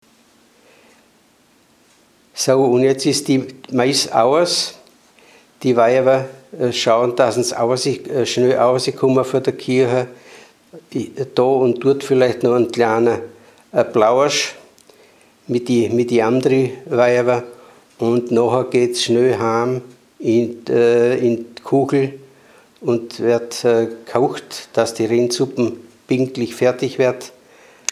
Mundart: Wuderscher Dialekt
Man hörte dabei eine gedehnte „ua“ Aussprache.
Unser Budaörser Heimatmuseum besitzt eine Sammlung von Tonaufnahmen in wunderbar gesprochenem Wuderscher Dialekt, den wir in einer Hörprobe präsentieren: